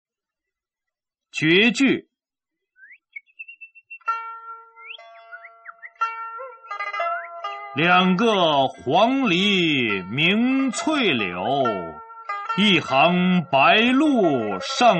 13 绝句 课文朗读MP3 苏教版三年级语文下册 第六册语文